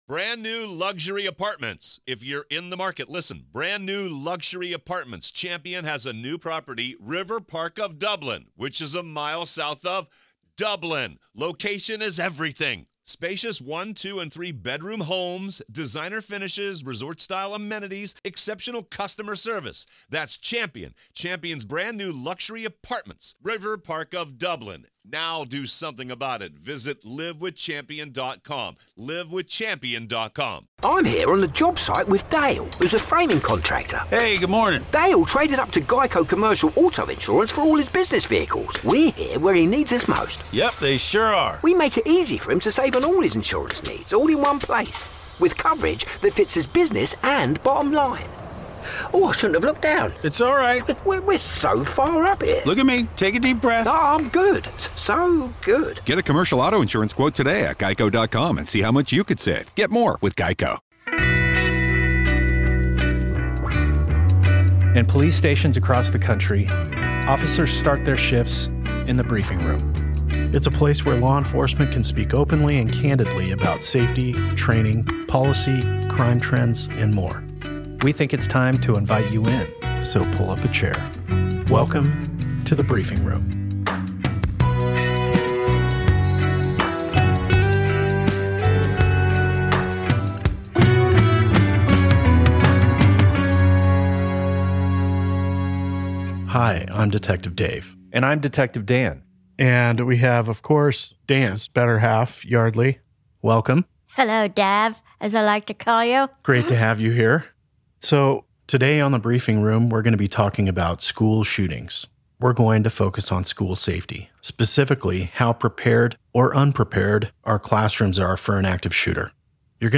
Guest host Yeardley Smith is also on-hand to ask the guys why they started this podcast.